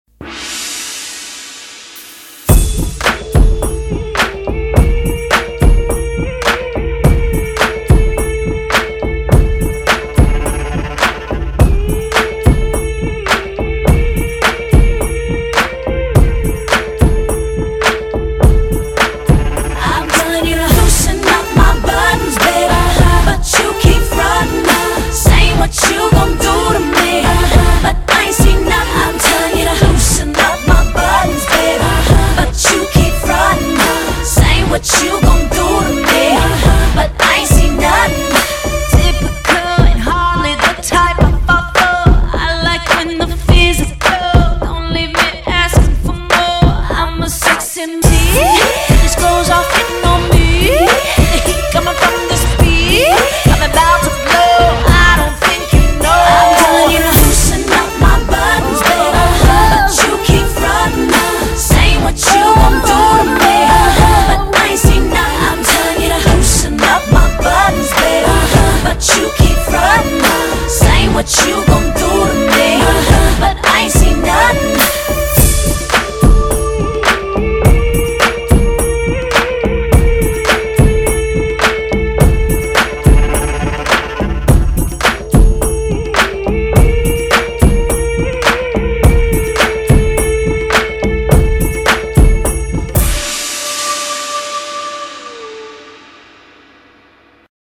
BPM131--1